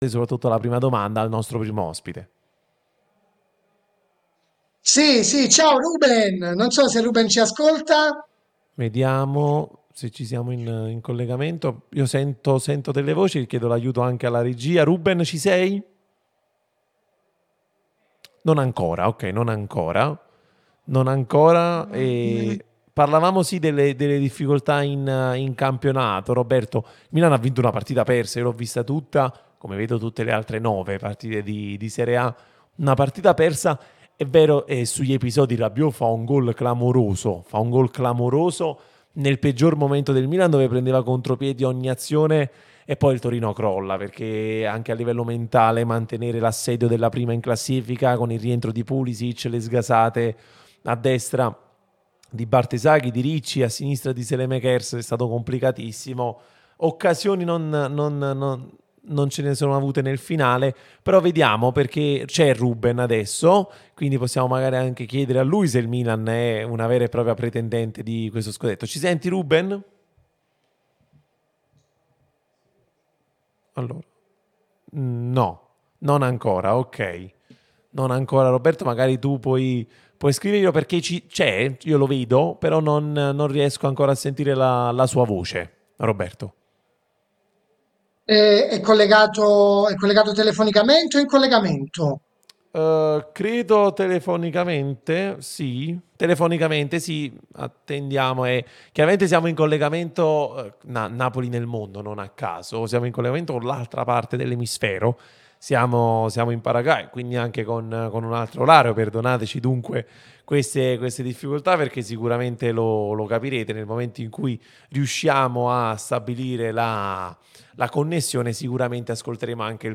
L'ex difensore del Napoli Ruben Maldonado è intervenuto nel corso di 'Napoli nel Mondo' sulla nostra Radio Tutto Napoli, prima radio tematica sul Napoli, in onda tutto il giorno, che puoi ascoltare/vedere qui sul sito o sulle app (qui per Iphone/Ipad o qui per Android): "Un grande Napoli, ma non solo perché ha conquistato la vetta.